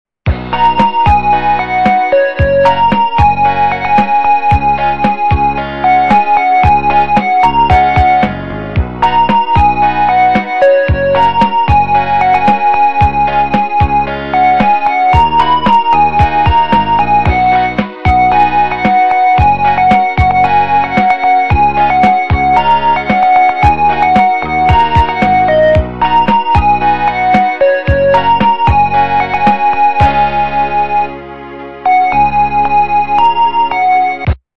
западная эстрада